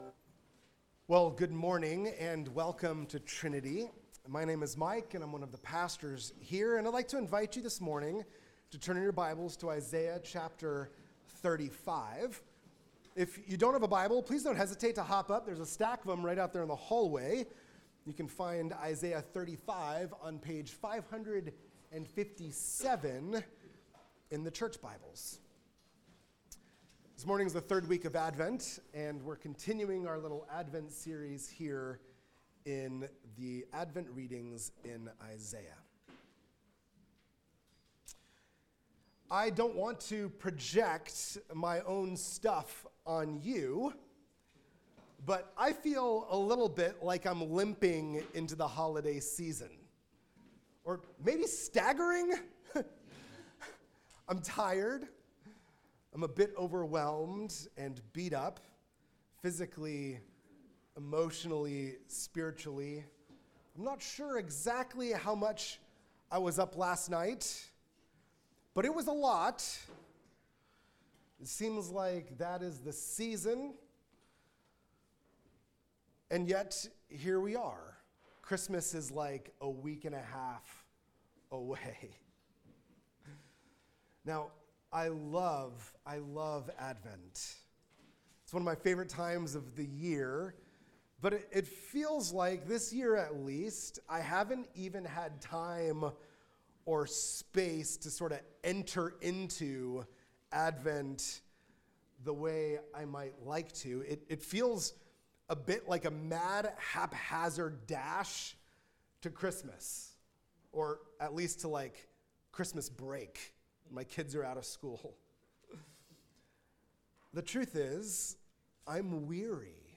This week’s sermon, based on Isaiah 35, acknowledges the weariness and anxiety many feel during the holiday season.